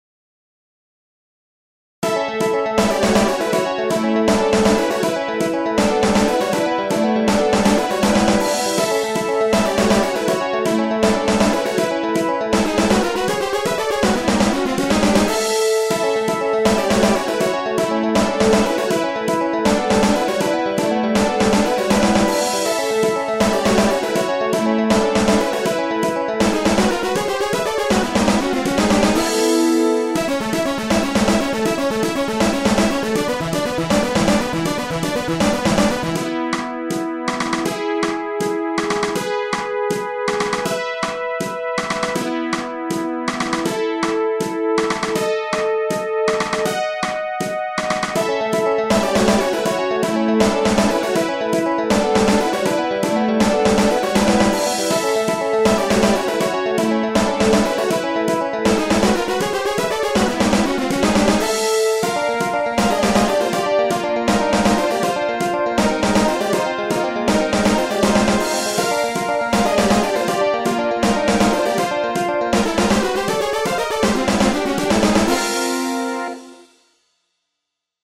昔のコナミのシューティングゲームみたいな曲を作りたかったんやけど、まだまだ力量不足やなあ・・。
今回初めてまともにドラム打ち込んだかも・・。